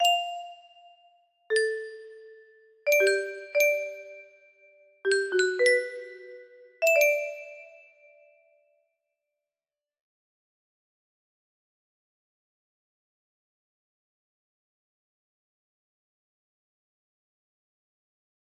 test2 music box melody